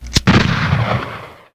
thunderstick.ogg